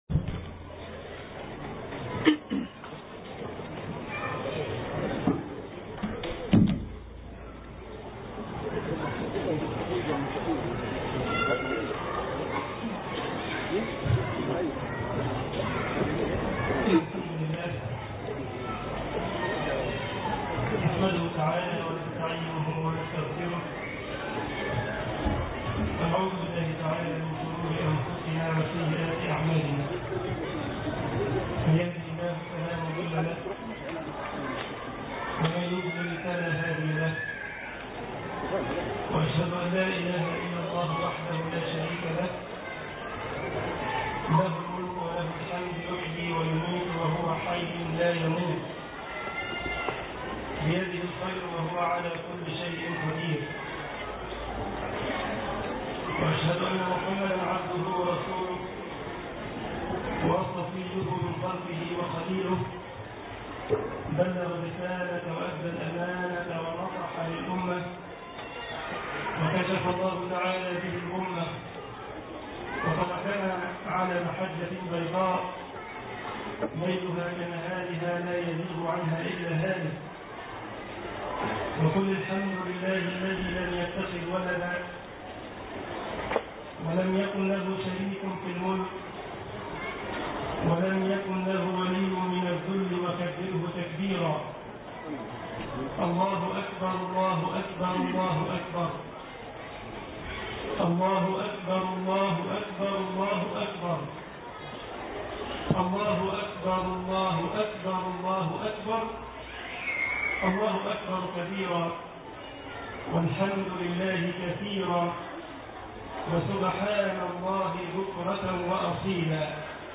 خطبة العيد
جمعية الشباب المسلمين بسلزبخ - ألمانيا